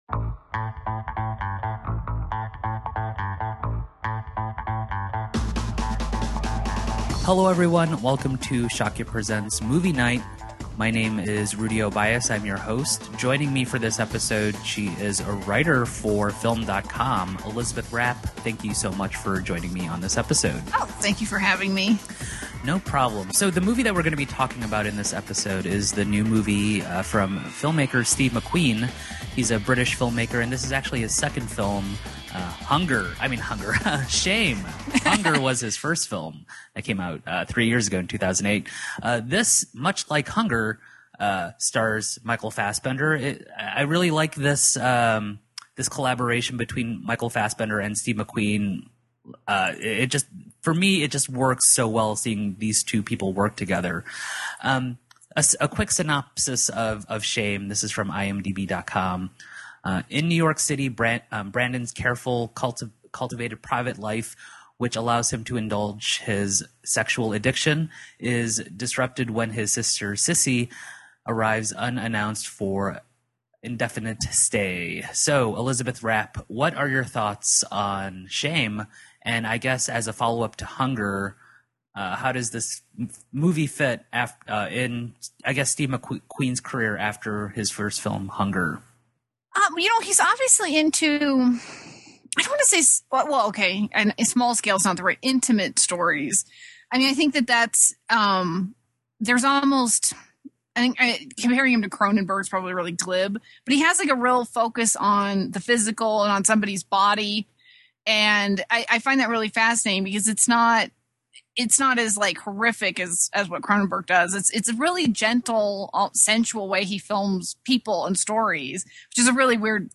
A discussion and review of the newest movie releases from your favorite entertainment and celebrity website!